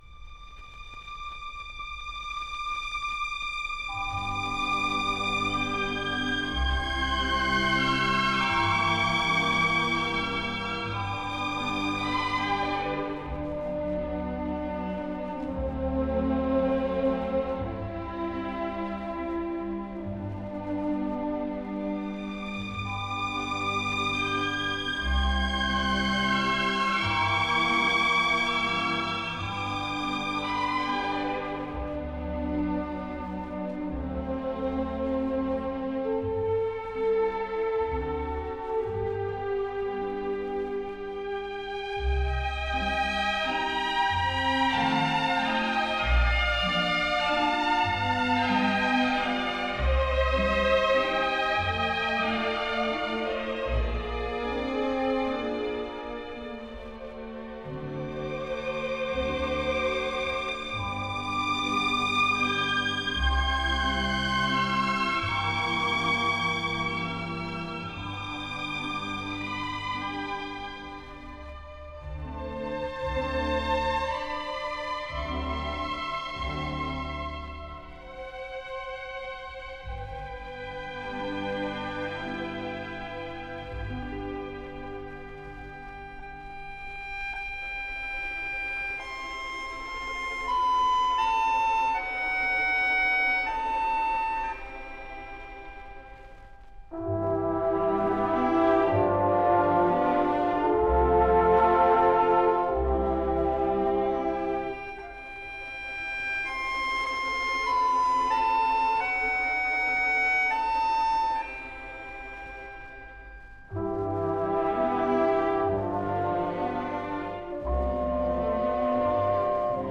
Genre: Jazz
Style: Easy Listening